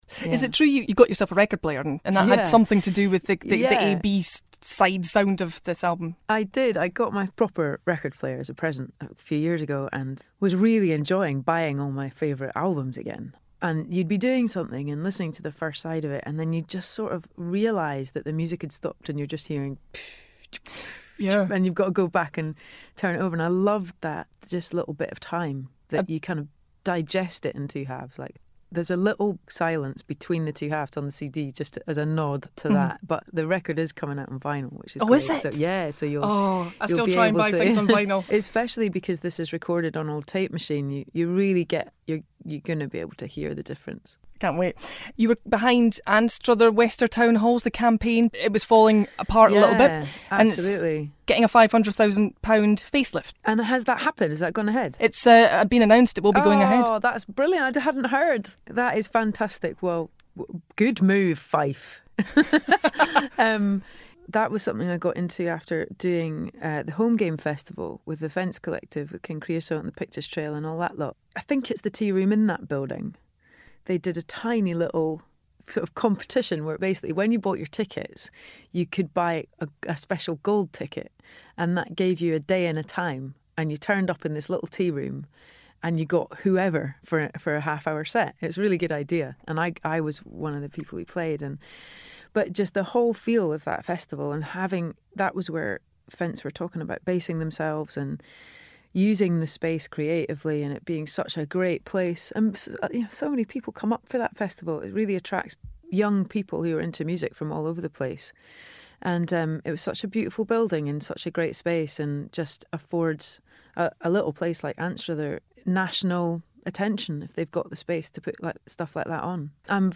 KT Tunstall chats to Kingdom Breakfast Part 2